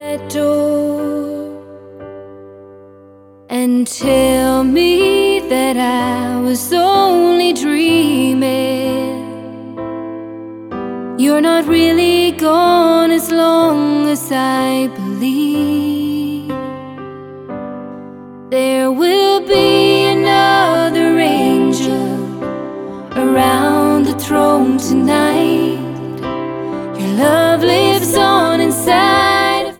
• Easy Listening